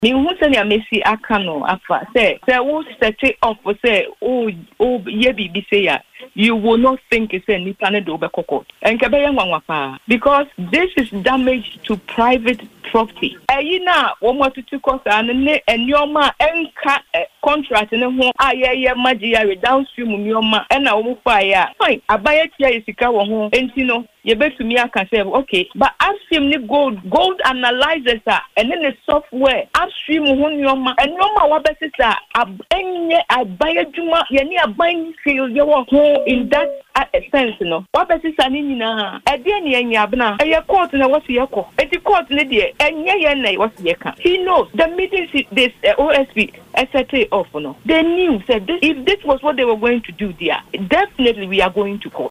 She said the following during the interview: